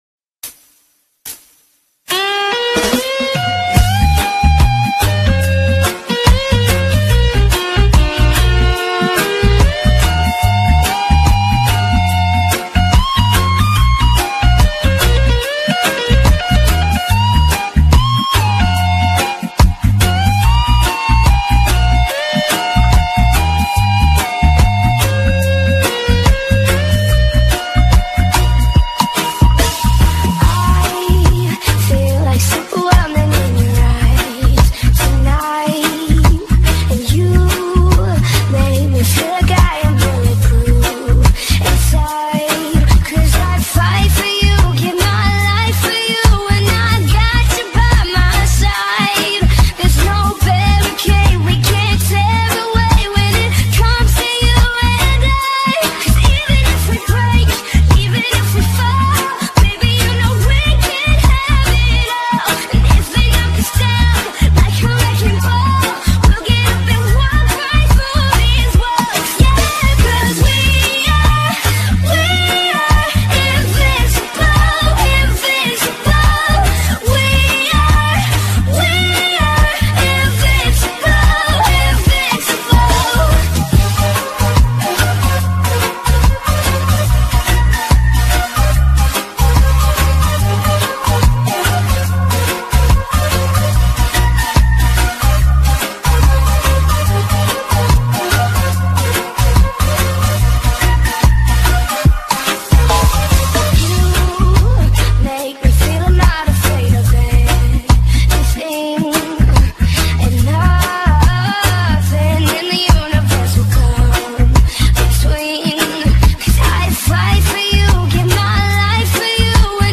SET 2017 REGGAE SÓ OS MELHORES DE 2017